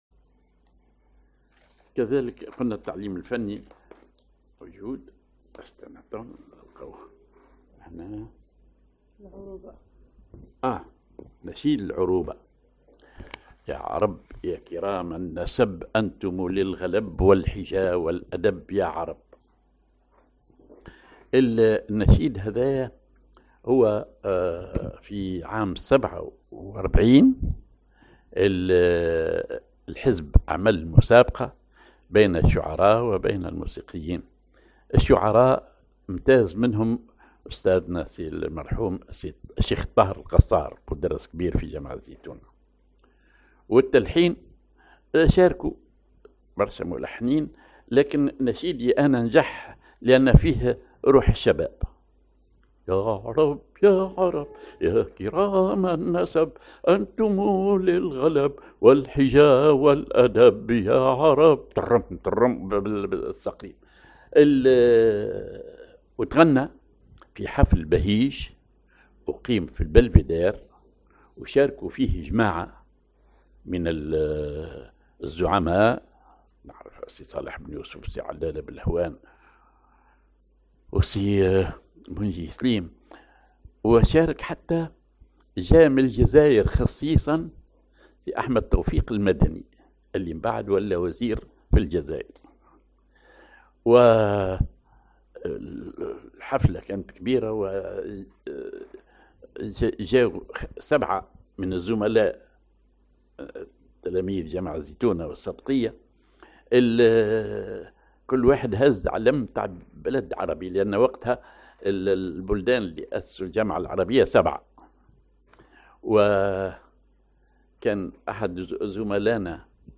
Maqam ar ماجور على العجم عشيران
Rhythm ar الخطوة
genre نشيد